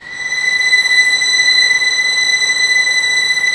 Index of /90_sSampleCDs/Roland - String Master Series/STR_Vlns 6 p-mf/STR_Vls6 mf amb